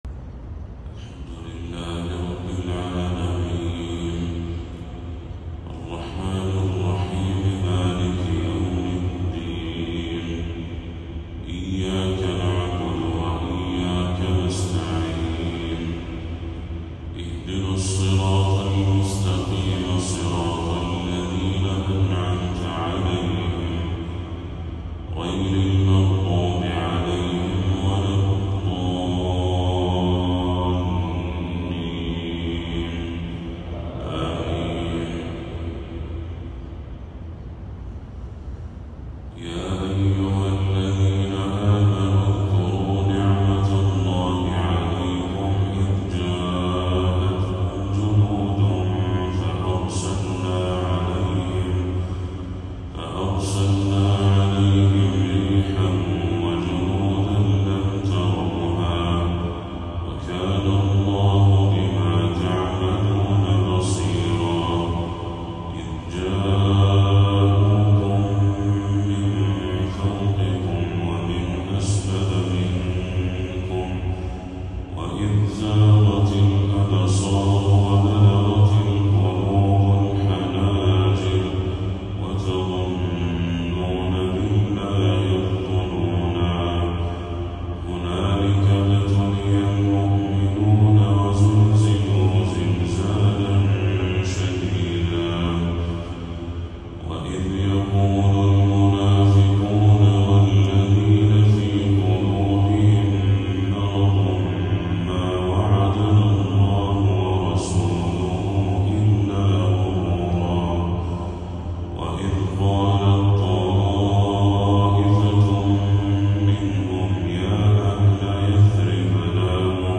تلاوة تُداوي القلب من سورة الأحزاب للشيخ بدر التركي | فجر 9 ربيع الأول 1446هـ > 1446هـ > تلاوات الشيخ بدر التركي > المزيد - تلاوات الحرمين